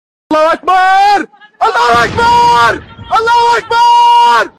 allahu akbar Meme Sound Effect
allahu akbar.mp3